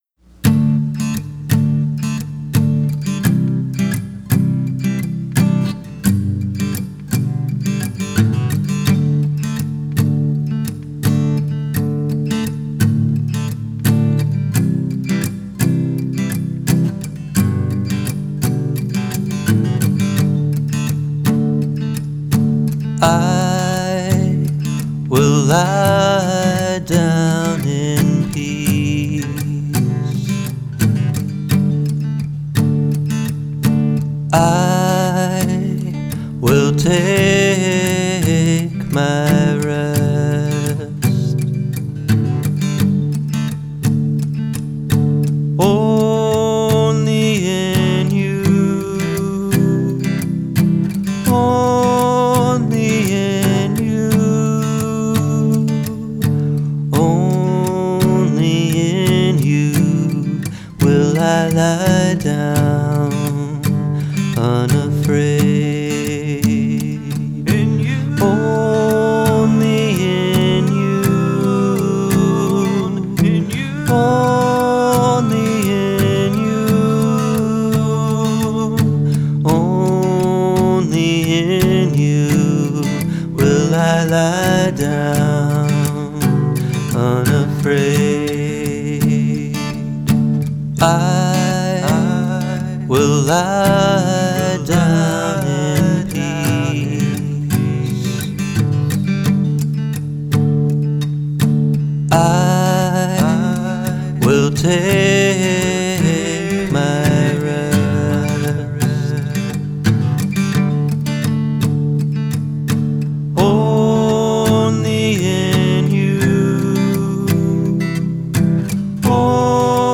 beautiful vocal harmonies